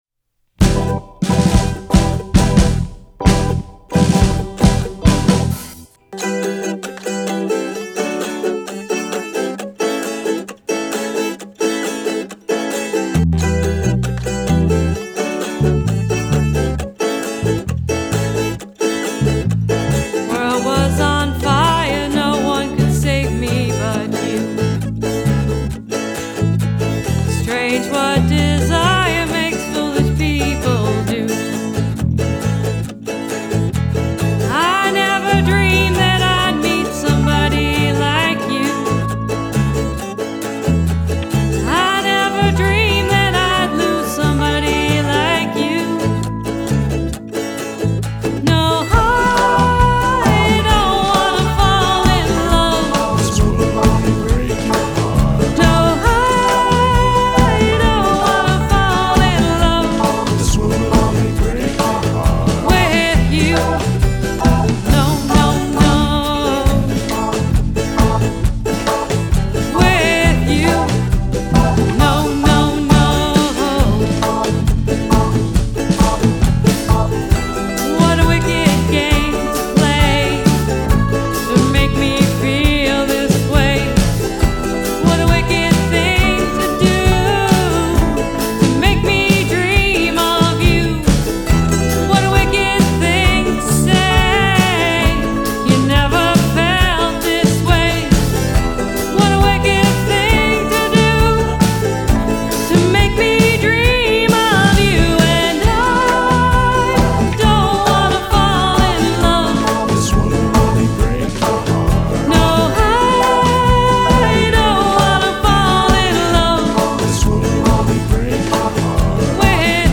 lightens up considerably in their rhumba-inspired make-over